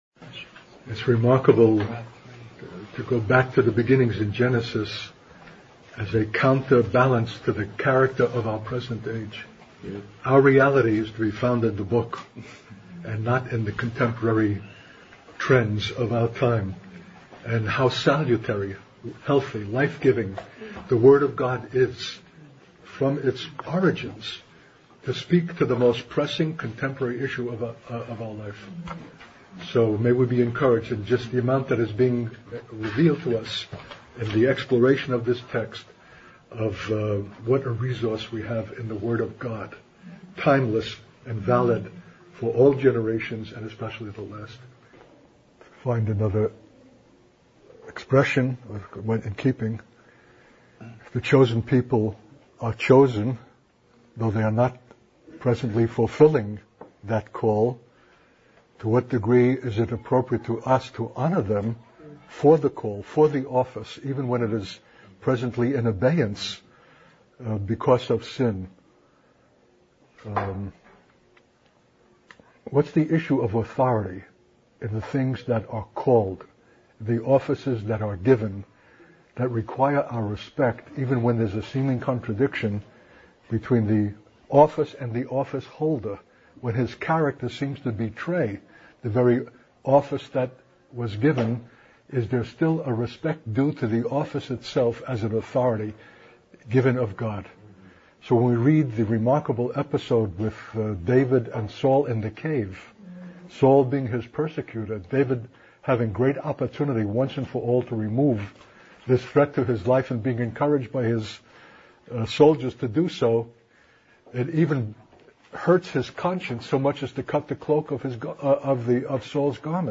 In this sermon, the speaker emphasizes the importance of the preaching of the word of God as foundational for life and reality.